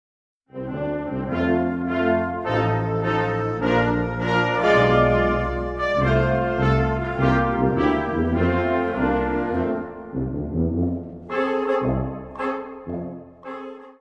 Catégorie Harmonie/Fanfare/Brass-band